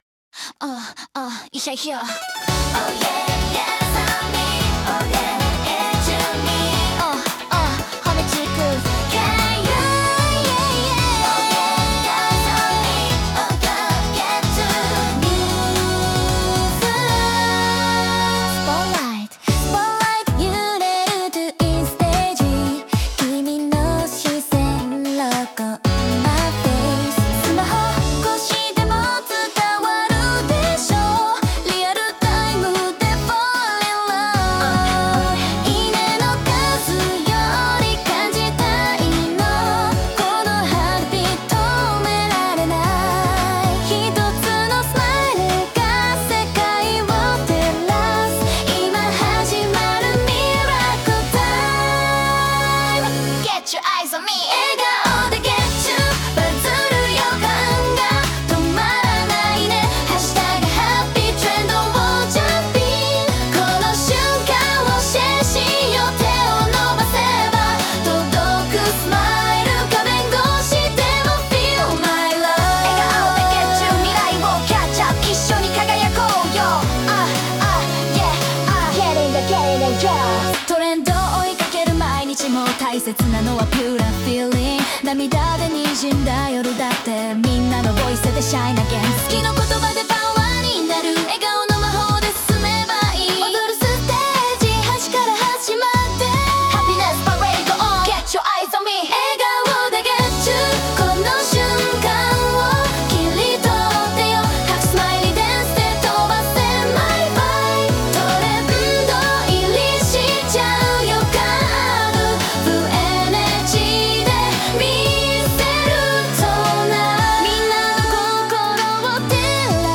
SNSで話題になること間違いなしのキュートなポップナンバー！